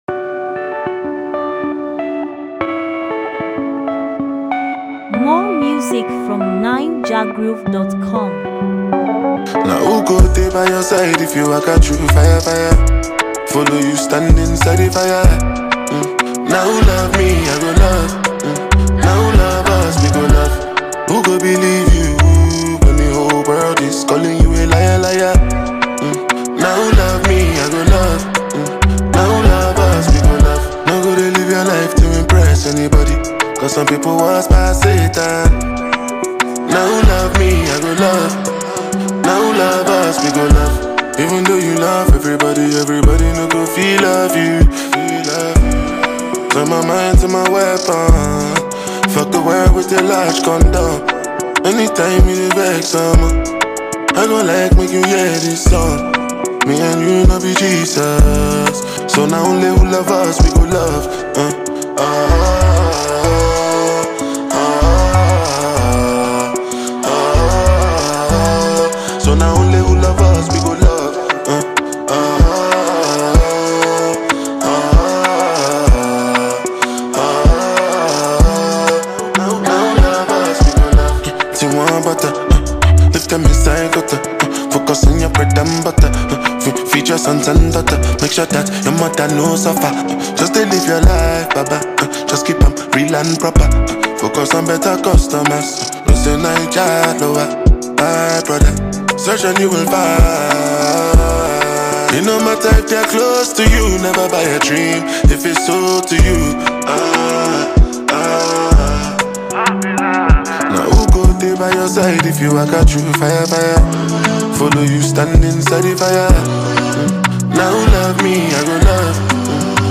Latest, Naija-music